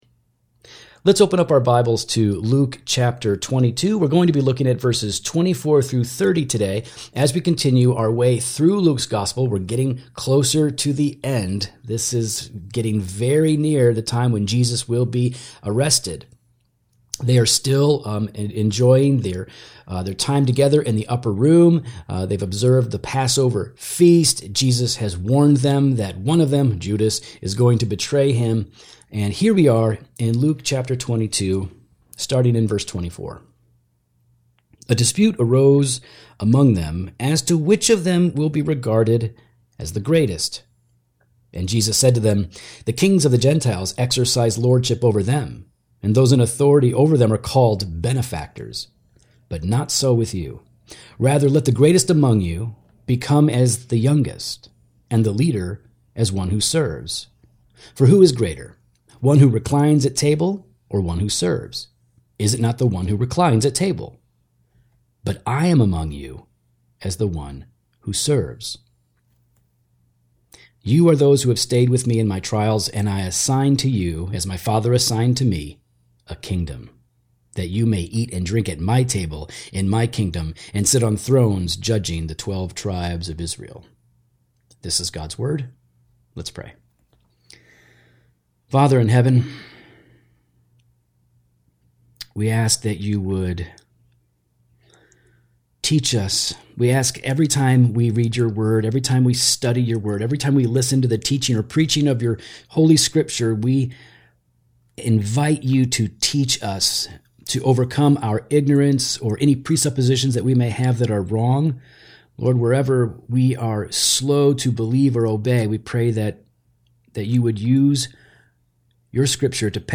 — Sermon text: Luke 22:24–30